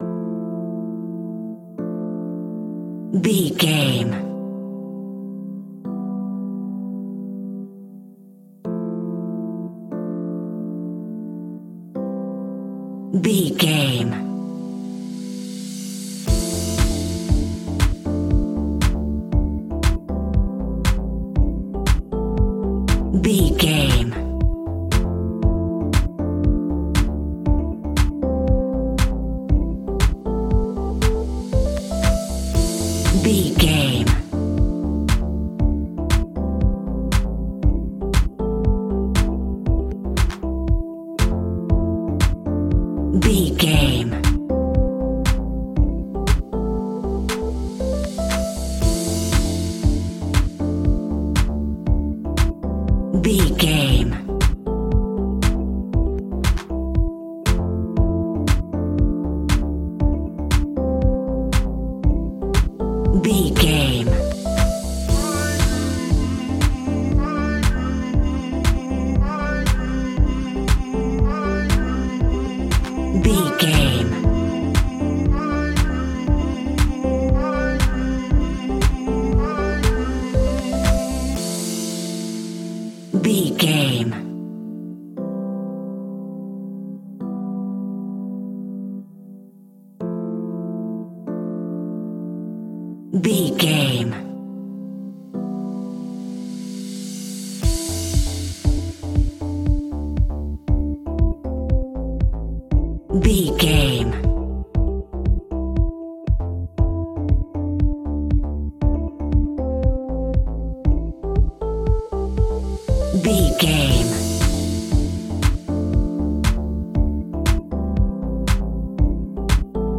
Ionian/Major
groovy
uplifting
driving
energetic
repetitive
bouncy
electric piano
synthesiser
drum machine
vocals
electronic
techno
synth leads
synth bass